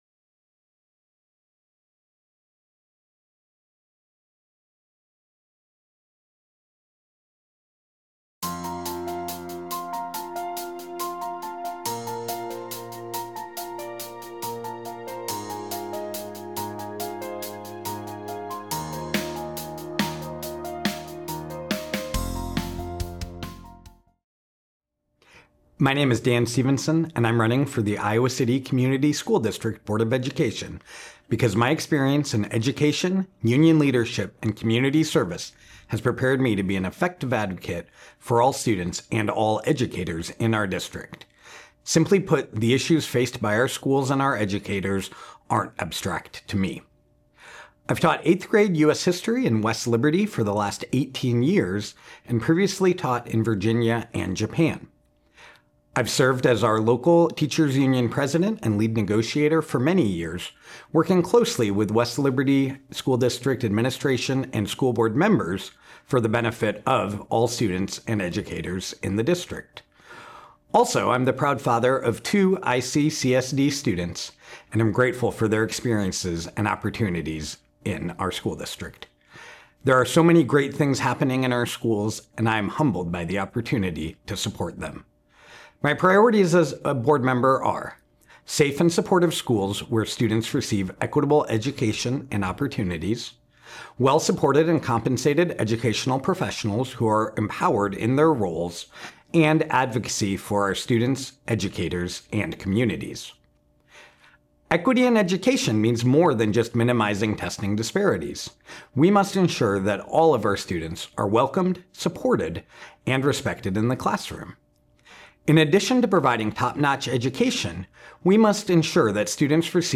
City Channel 4's Meet the Candidates project invited all of the registered candidates for the November 4th Iowa City Community School District Board of Directors election to come to our studio to present themselves to voters in five minutes or less.